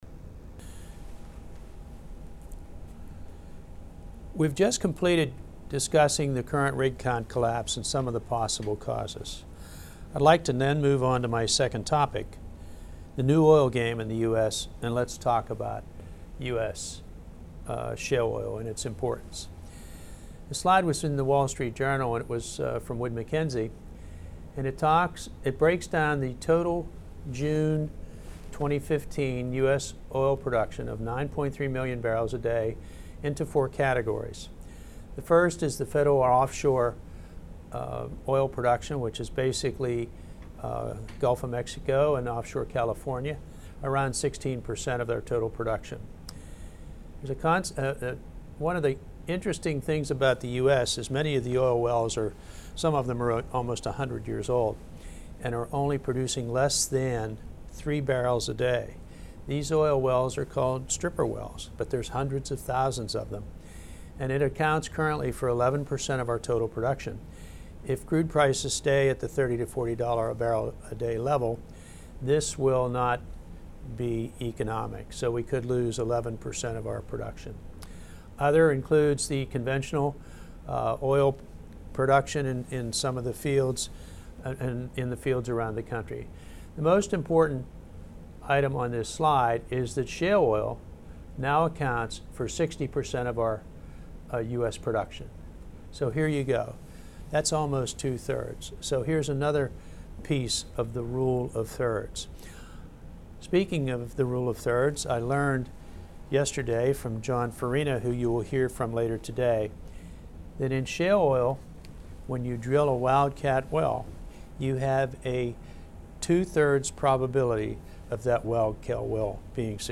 a regular day-long training seminar offered by the SPE here in Houston. This presentation has become the most popular seminar offered by the SPEGCS over the years.